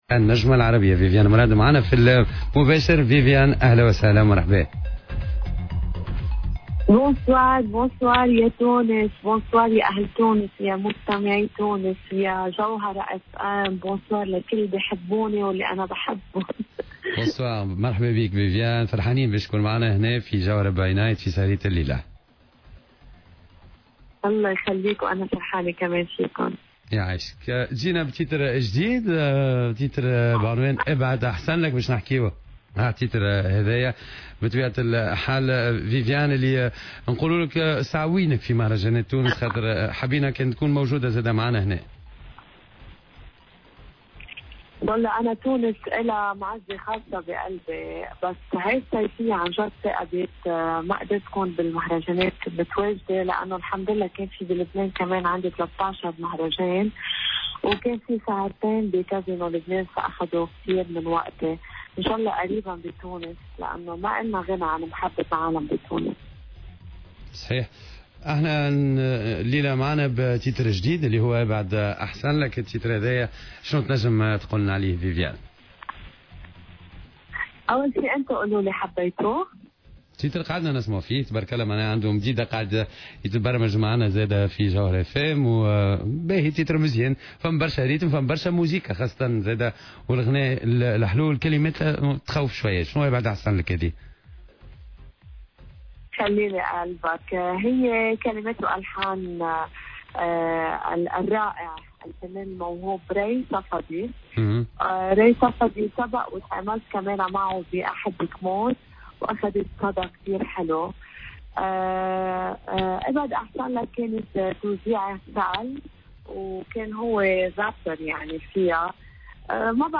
في حوار مع الجوهرة أف أم